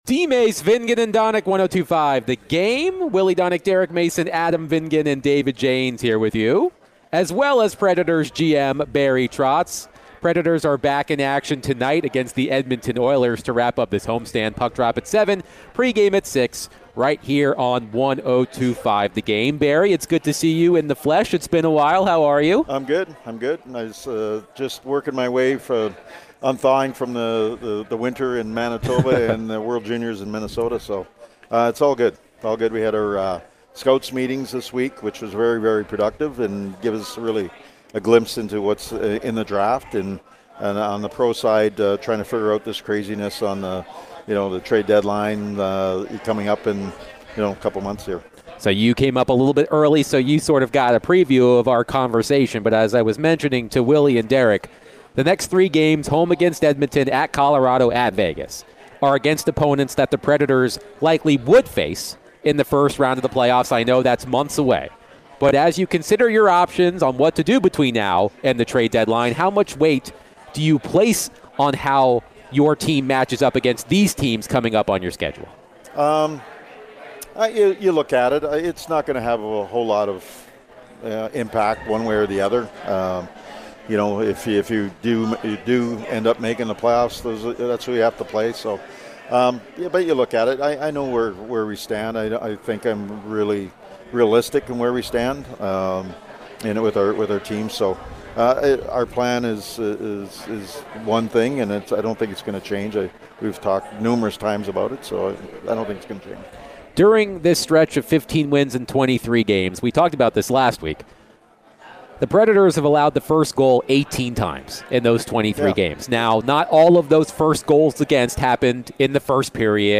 Nashville Predators General Manager Barry Trotz joined DVD for his weekly chat!